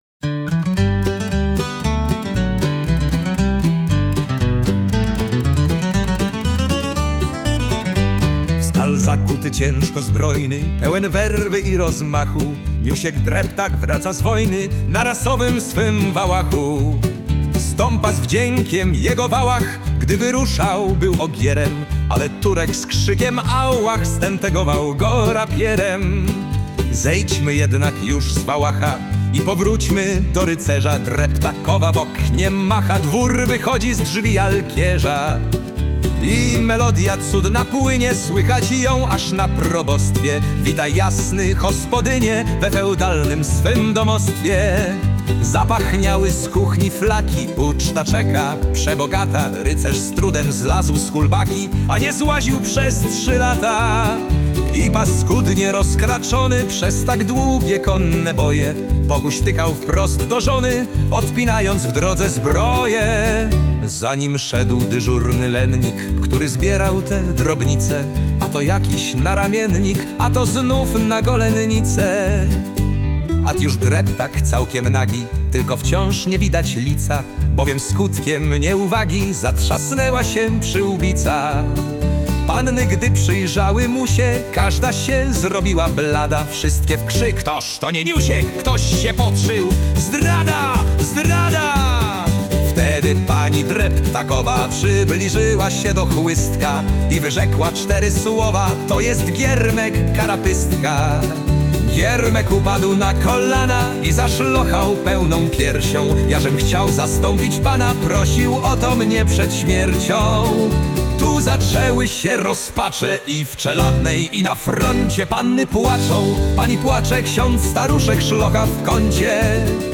(cover) Klasyka w nowej odsłonie.